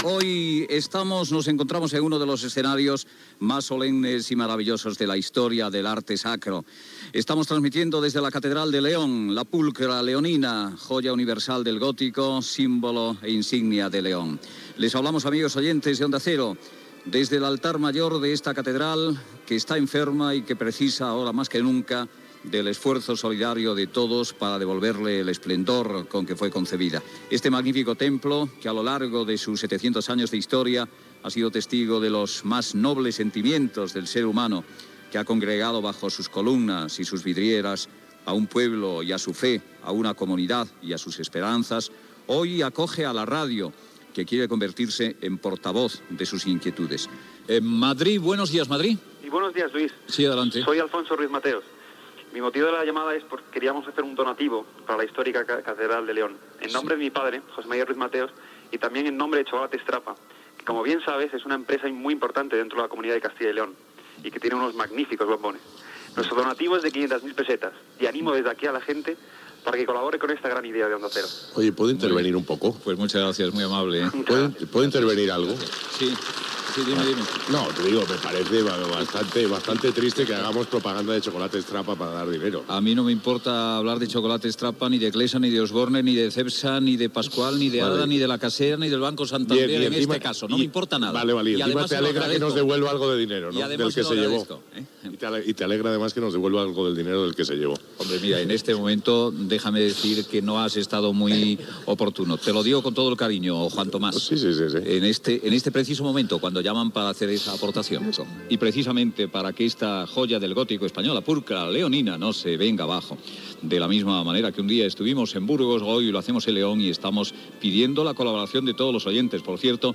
Resum de l'emissió des de la Catedral de León. Donatius per les obres de recuperació de l'edifici.
Info-entreteniment